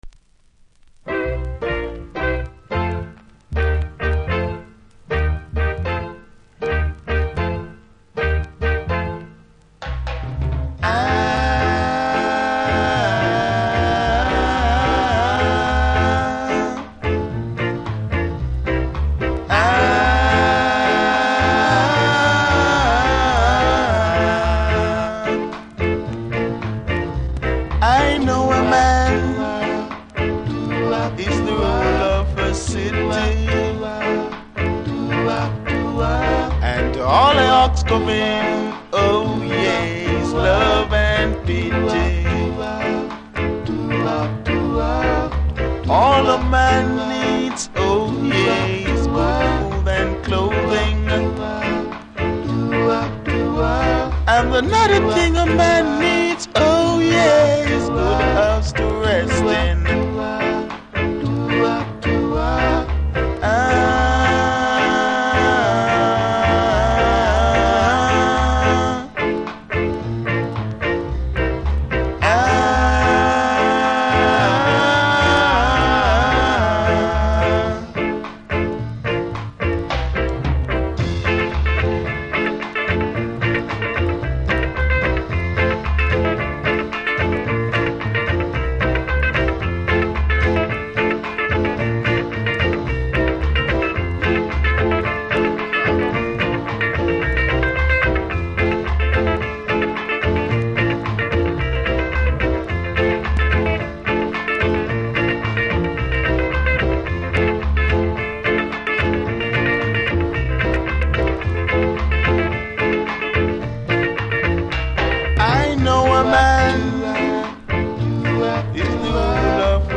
REGGAE 70'S
後半キズによりノイズ感じますので試聴で確認下さい。